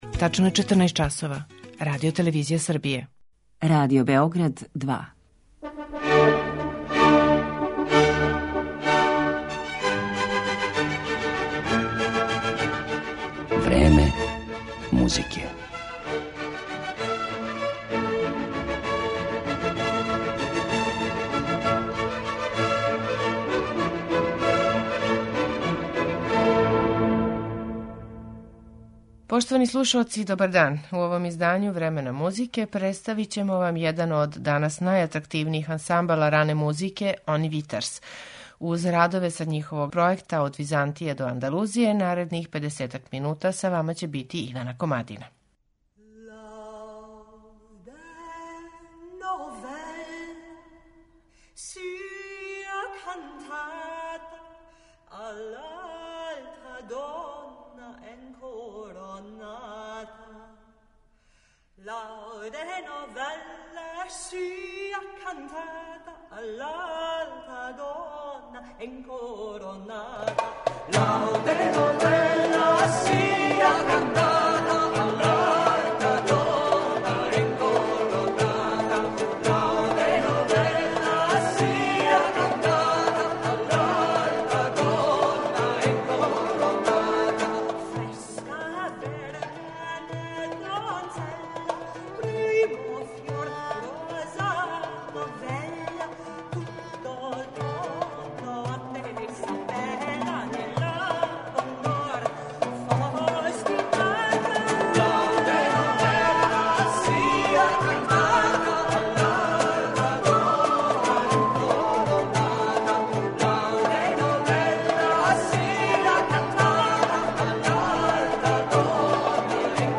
промовишу жанр познат као рана музика.